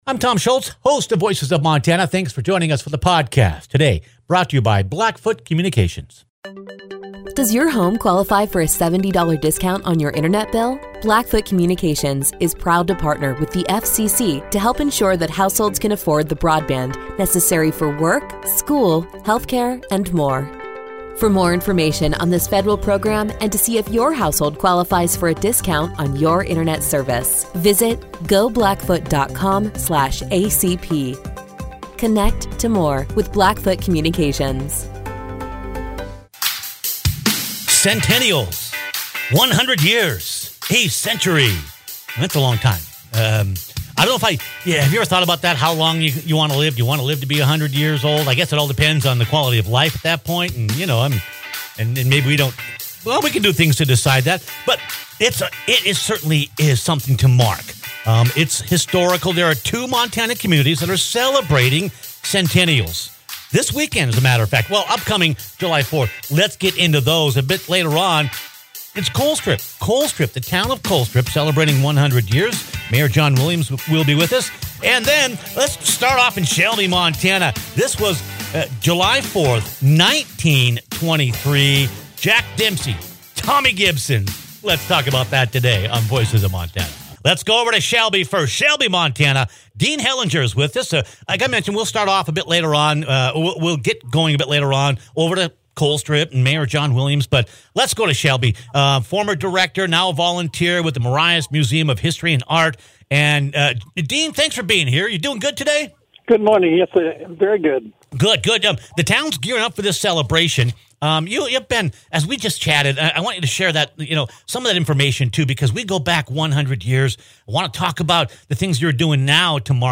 Then Colstrip Mayor John Williams calls in to talk about the 100 year journey of Montana’s iconic coal town.